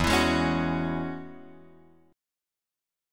E6add9 chord {0 x 2 1 2 2} chord